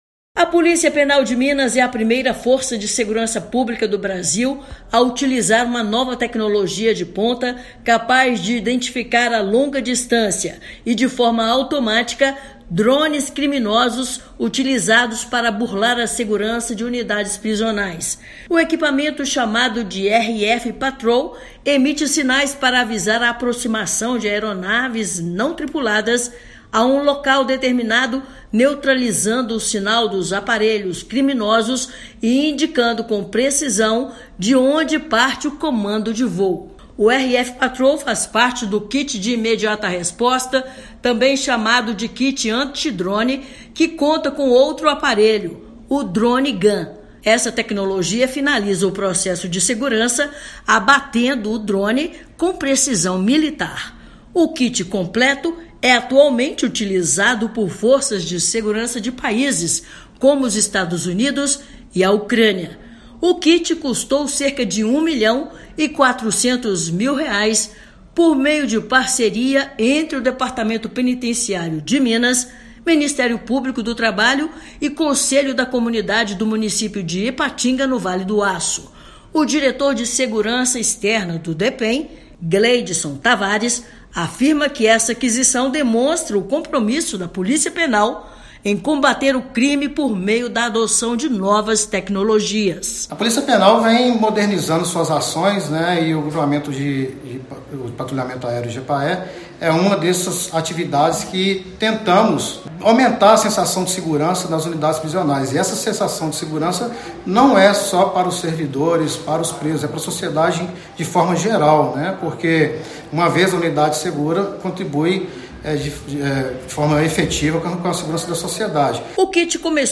Kit é capaz de rastrear aparelho criminoso a distância e abatê-lo com precisão militar, além de indicar origem do comando de voo. Ouça matéria de rádio.